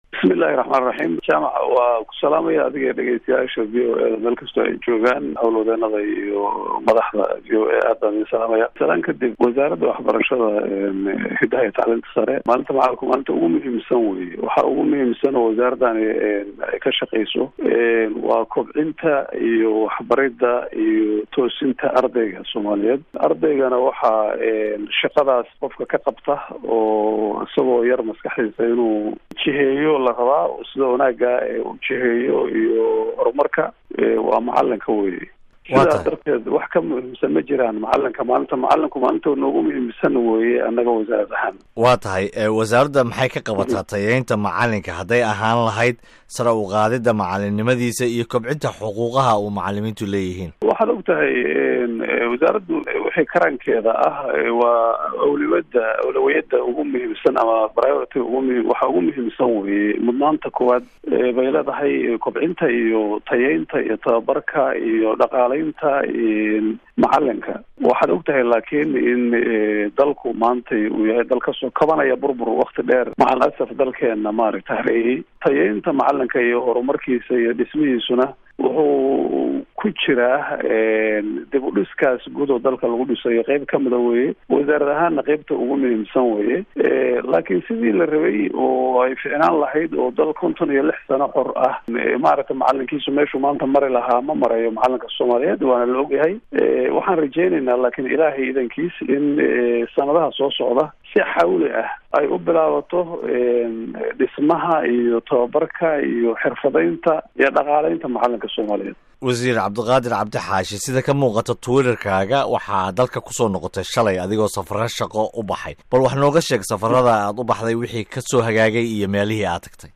Wareysi: Wasiirka Waxbarashada Soomaaliya